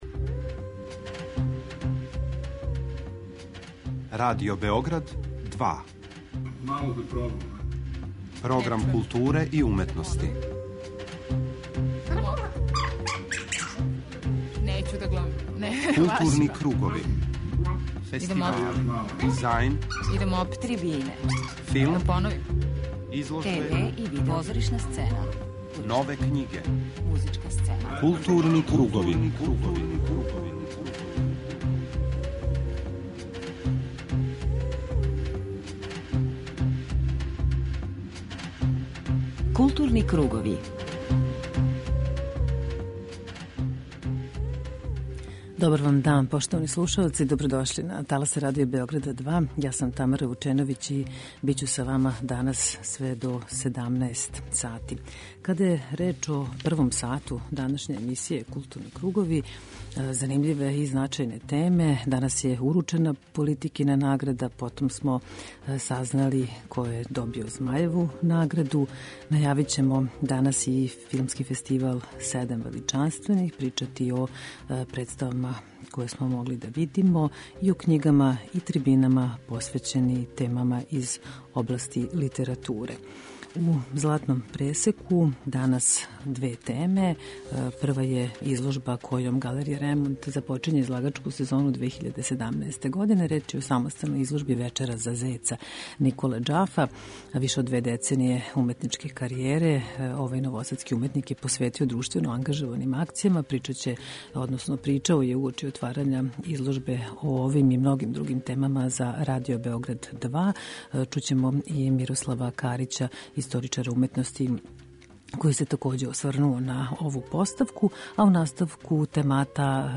У данашњем Златном пресеку, чућете разговор са oвим уметником.